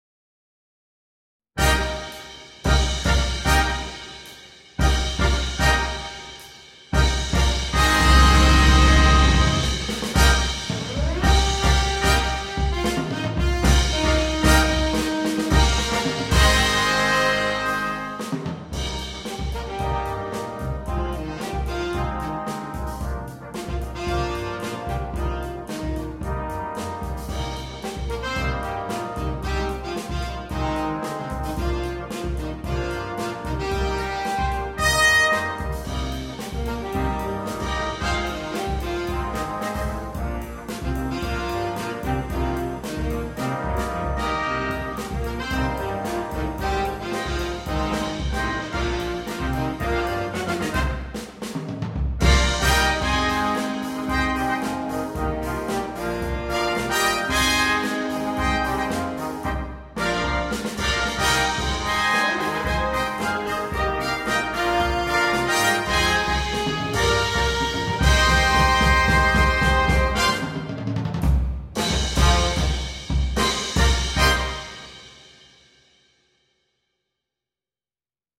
на биг-бэнд.